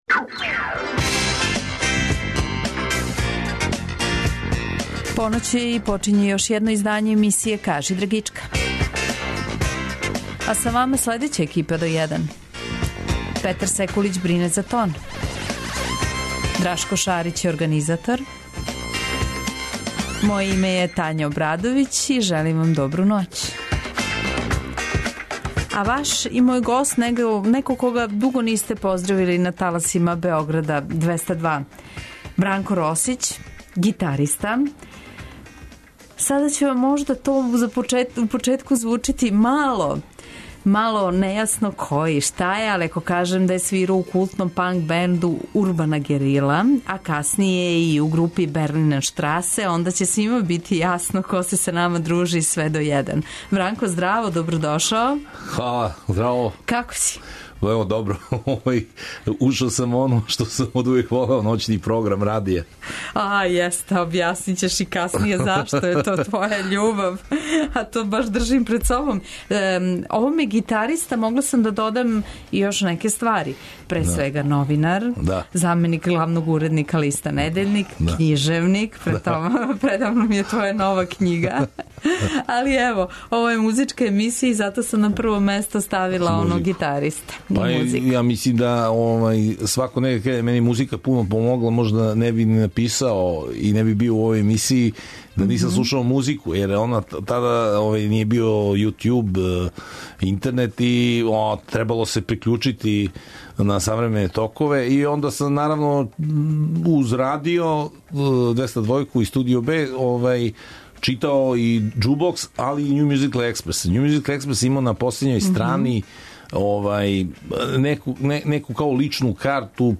Свако вече, од поноћи на Двестадвојци у емисији Кажи драгичка гост изненађења!